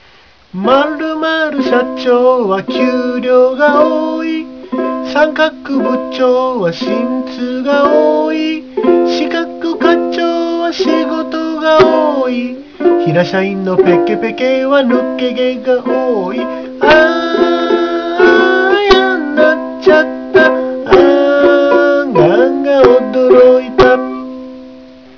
ウクレレ漫談
歌声（１部のみ）